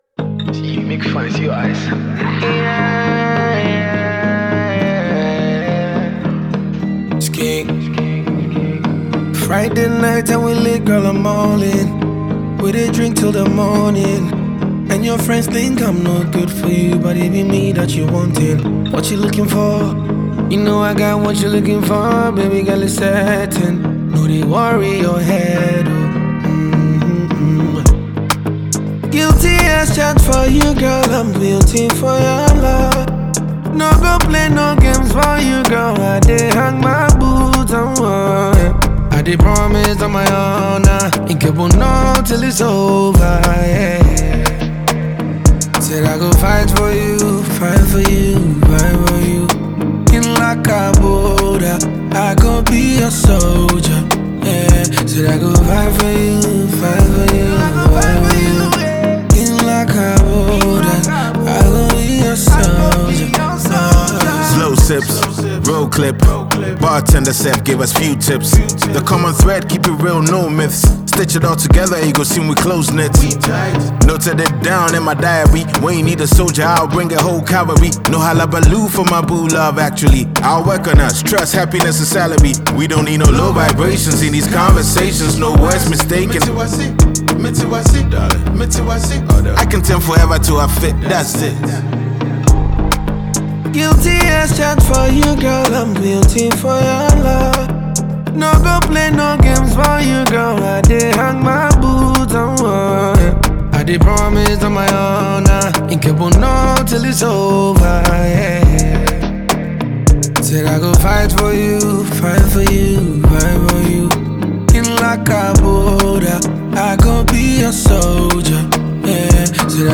beautifully blends rap and soulful melodies